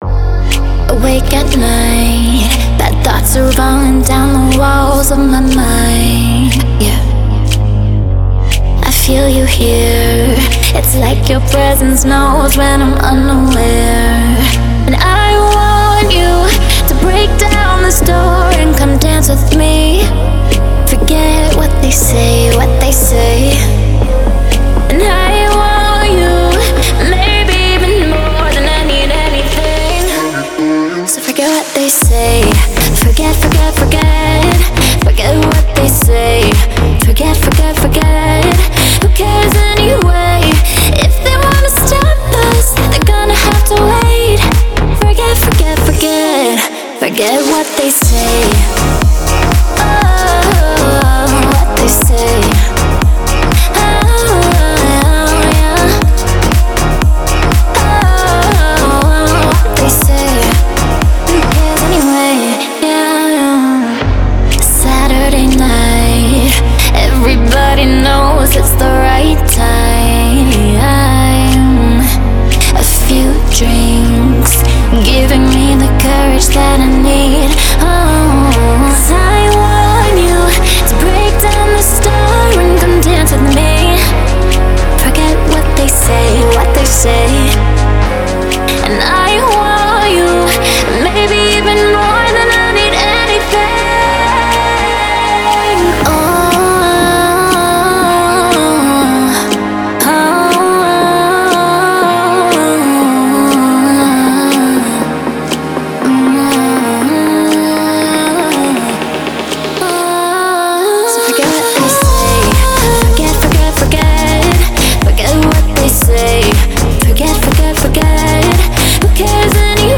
это трек в жанре поп с элементами электроники
мягкие вокалы